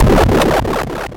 愤怒的鸡" 鸡叫声 严厉的
描述：一只加重的禁止摇滚母鸡的尖叫声。一声响亮，绝望的尖叫，上面写着“别管我！”使用置于巢箱内的麦克风录制。
标签： 生物 尖叫 声音效果 发声 母鸡
声道立体声